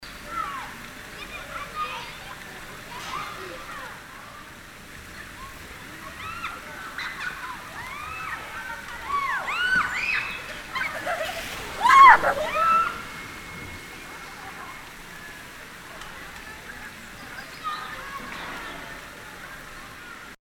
Royalty free sounds: Children
mf_SE-5482-open_air_bath_waterslide_3.mp3